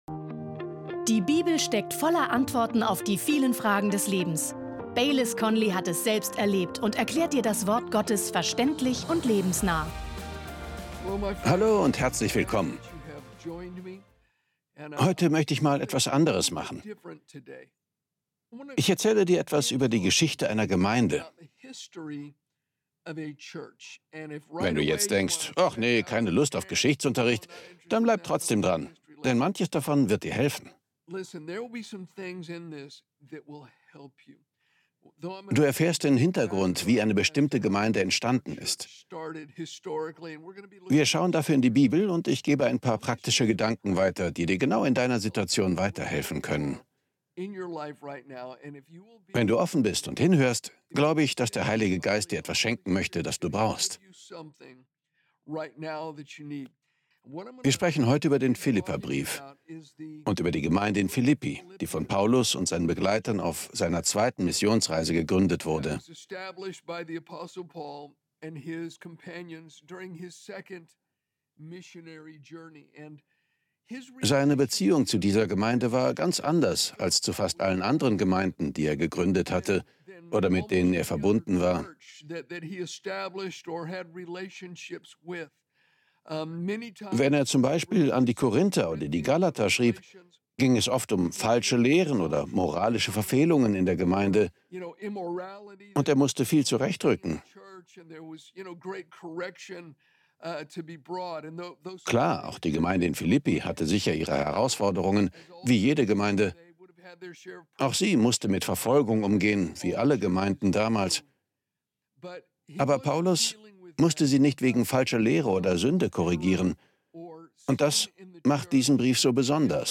Du wirst entdecken, warum sowohl Liebe als auch Weisheit dafür wichtig sind. In seiner Predigt